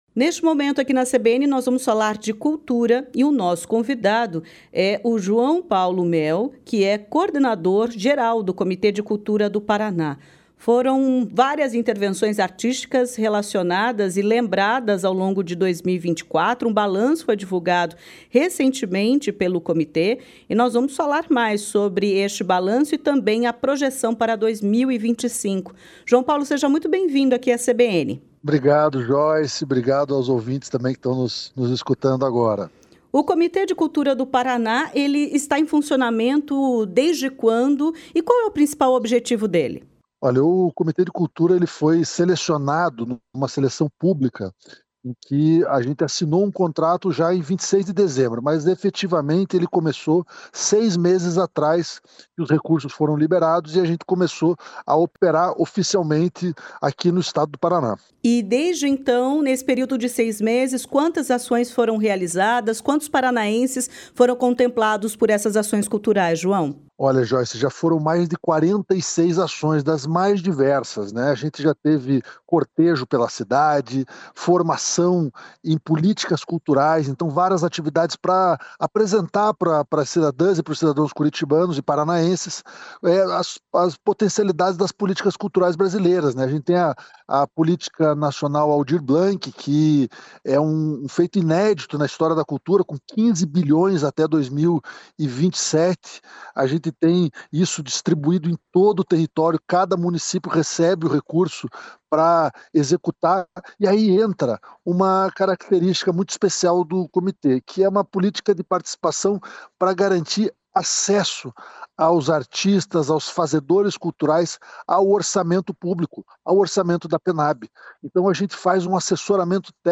ENTREVISTA-0-04.01-COMITE-CULTURA-PR.mp3